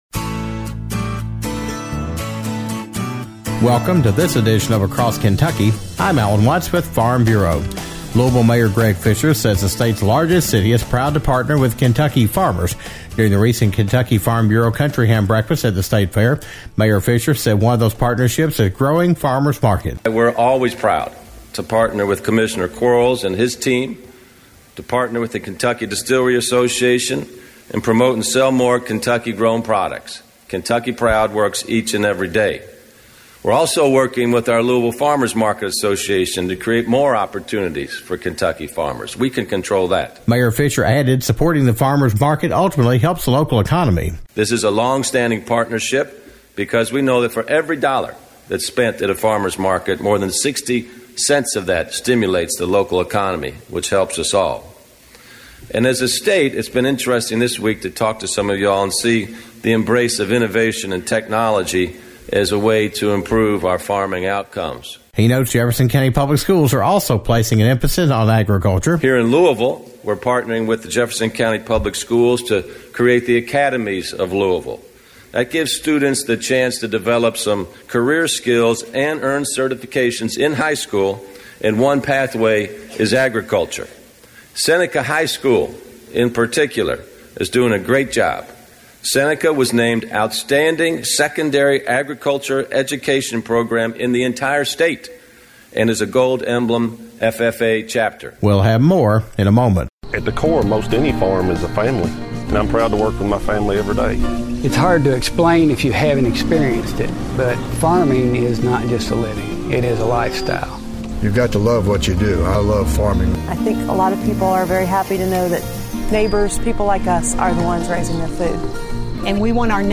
Louisville Mayor Greg Fischer says the city he is leading is appreciative of the farmers that produce the food and fiber that make Louisville a hub for restaurants and tourism.  During the recent Kentucky Farm Bureau Country Ham Breakfast at the Kentucky State Fair, Mayor Fischer talked about the partnership and how the city benefits from agriculture.